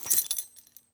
foley_keys_belt_metal_jingle_06.wav